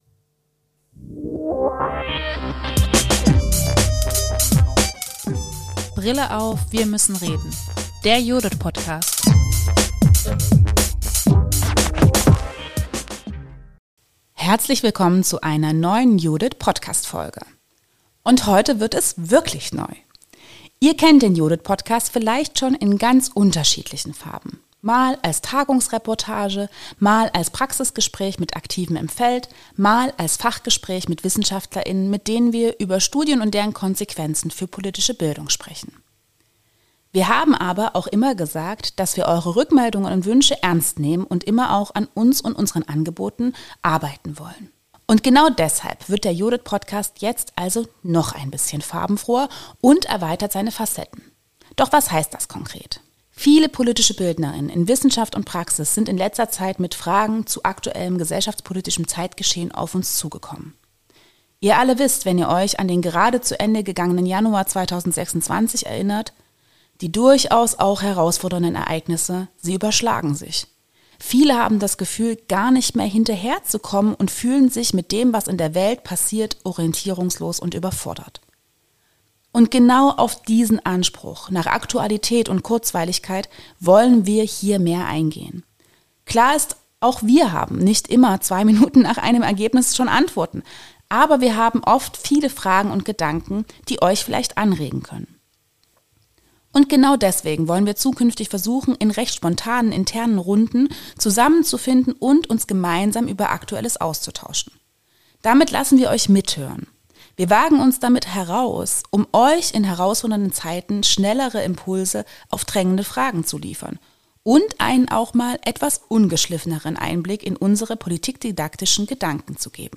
Daher werden wir zukünftig in recht spontanen internen Runden zusammenfinden und uns gemeinsam über Aktuelles austauschen. Wir lassen euch mithören und wagen uns heraus, um euch in herausfordernden Zeiten schneller Impulse auf drängende Fragen zu liefern und auch einmal einen ungeschliffenen Einblick in unser politikdidaktisches Denken zu geben. In dieser Folge thematisieren wir zwei sehr aktuelle Beispiele aus der aktuellen Medienberichterstattung zu se*xualisierter Gewalt: die Epstein Files und den Fall Gisèle Pelicot. Wie kann politische Bildung eigentlich mit solchen Fällen umgehen?